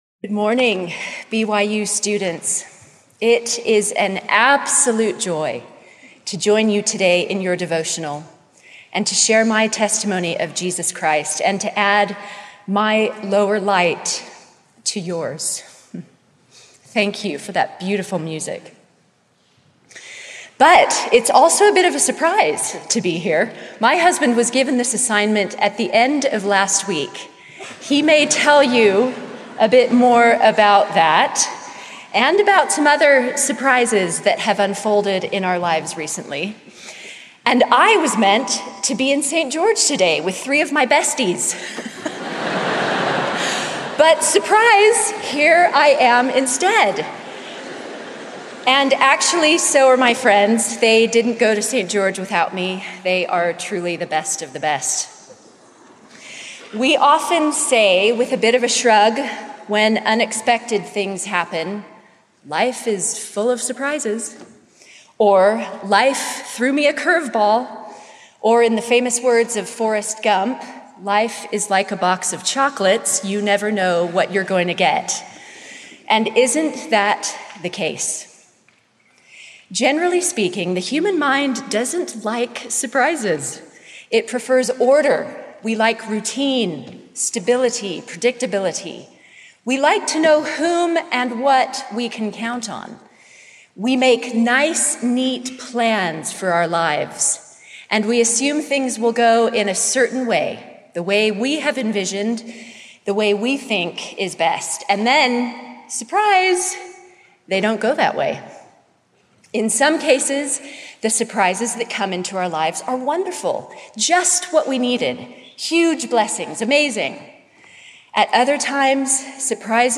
Click to copy link Speech link copied Devotional Surprise!